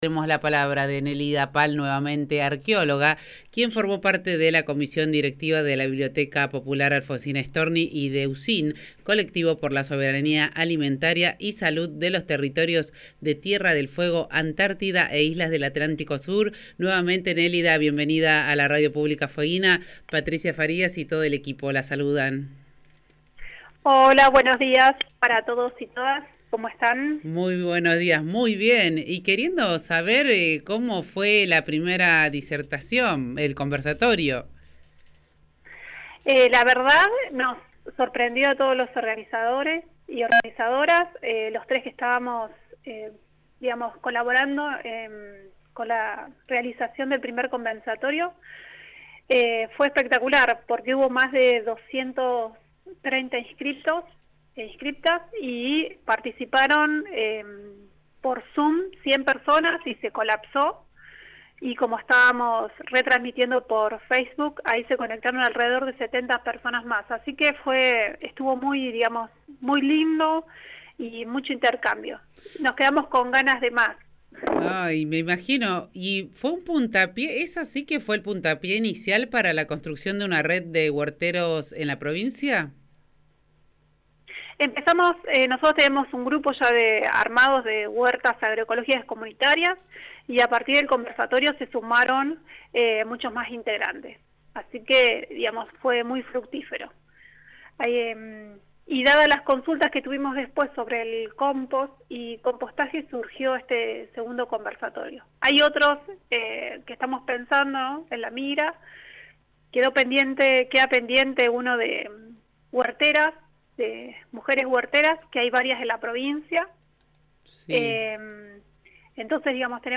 en dialogo con FM 103.1